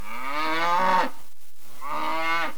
cow1.mp3